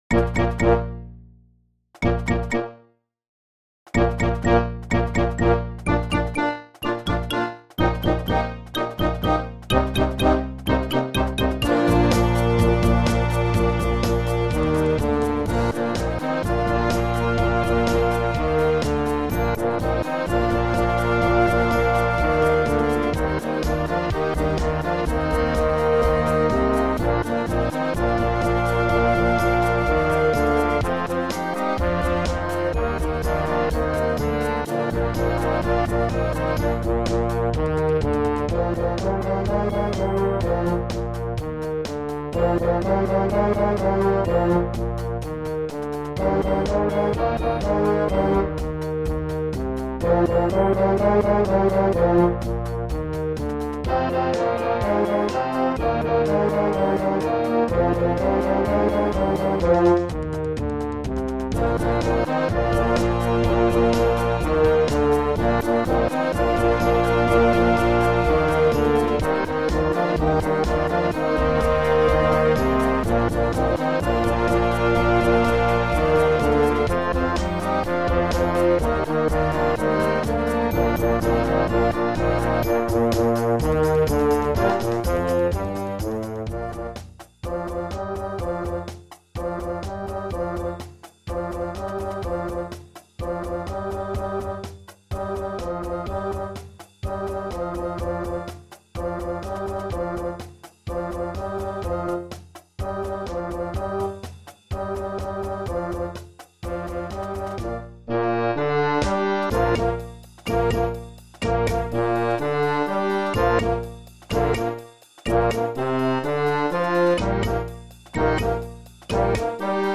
16M1 Young Concert Band $55.00